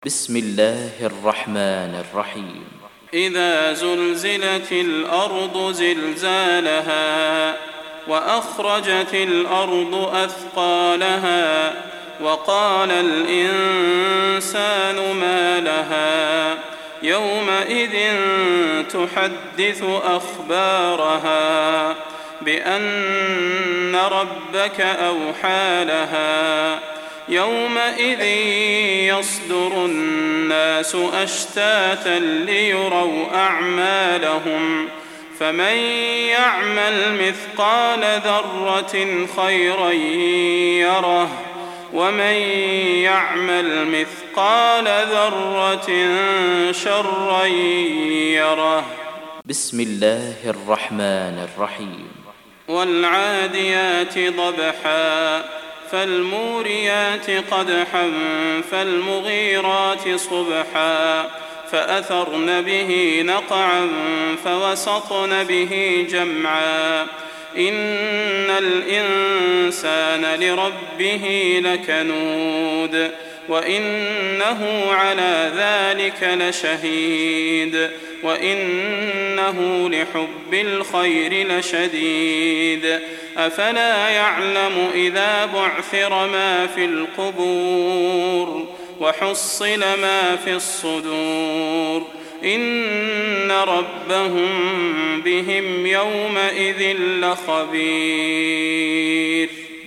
فروض مغرب البدير 1420